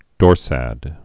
(dôrsăd)